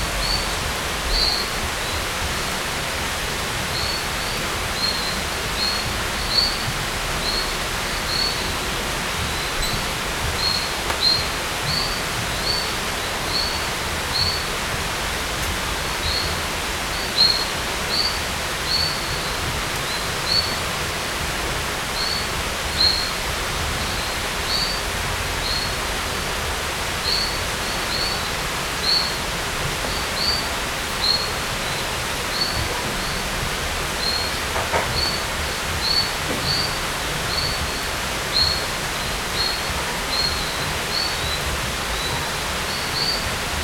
Sonidos del Pura Vida | BioSonidos - Colección de Sonidos de la Naturaleza
Ranitas de cristal en el patio HEREDIA